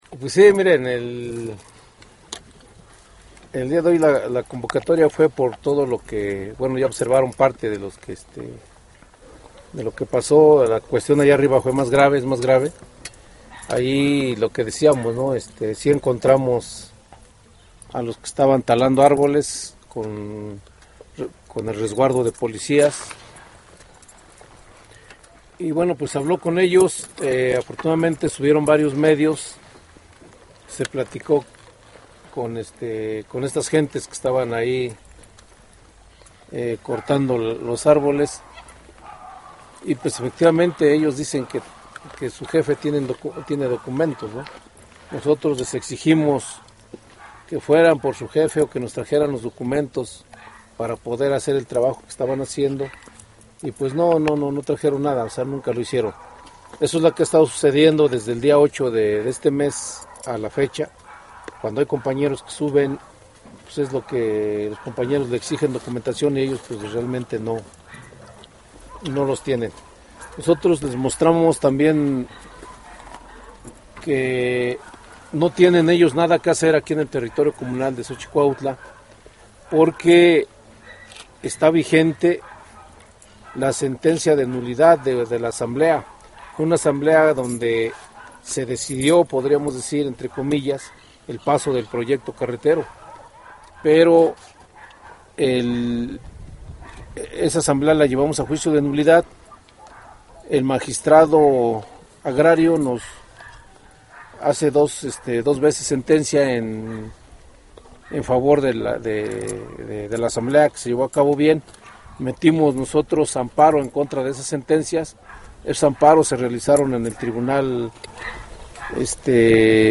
Entrevista con el FPIDMT